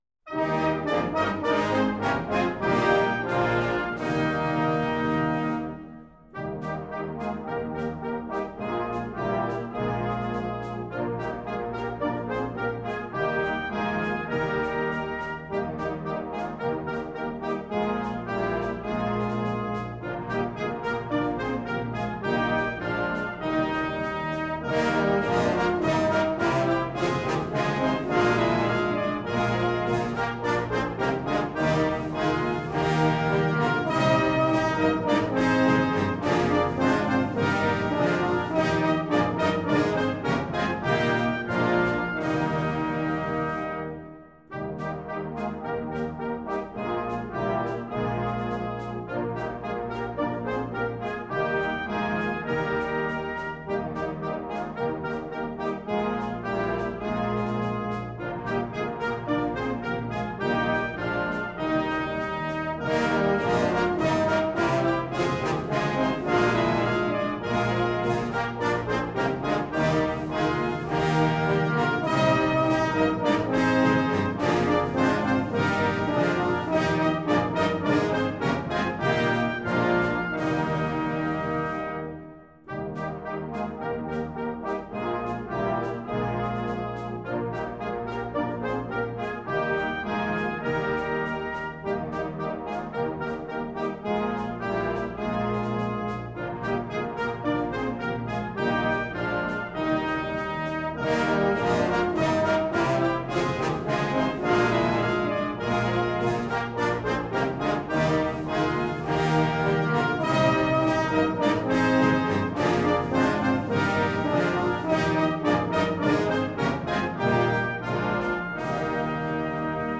Key: E♭
Tempo: 84
Metronome:     ♩ = 84